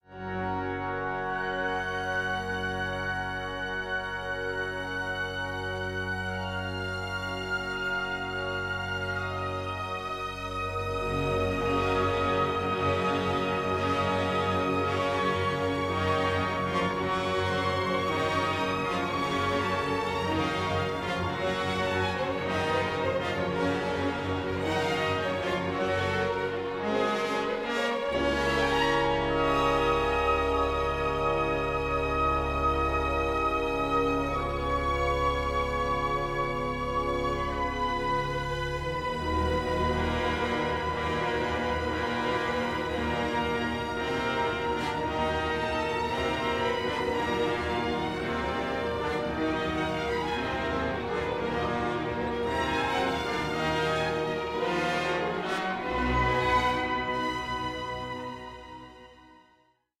MUSICAL ODE TO SUBLIME NATURE